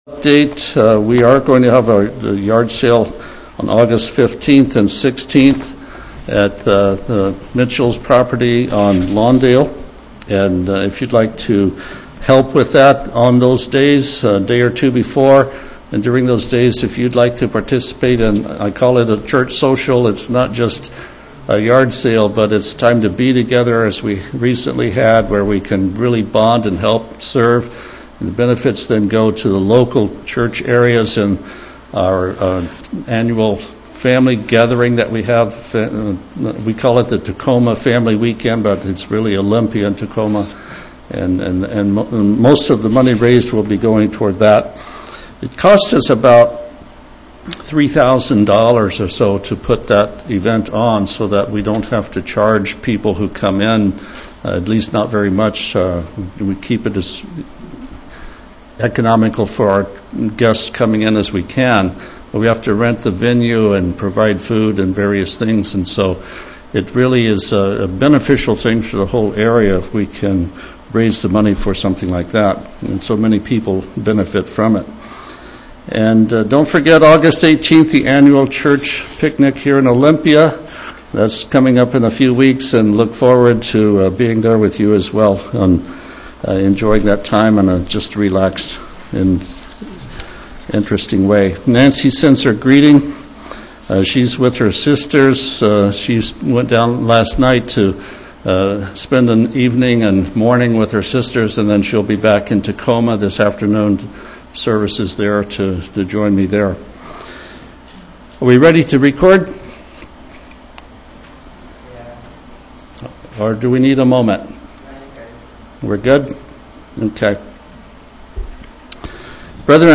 Handout ~ Part two in sermon series on “The Covenants” ~ Olympia & Tacoma ~ July 13